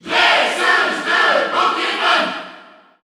Crowd cheers (SSBU) You cannot overwrite this file.
Pokémon_Trainer_Female_Cheer_French_SSBU.ogg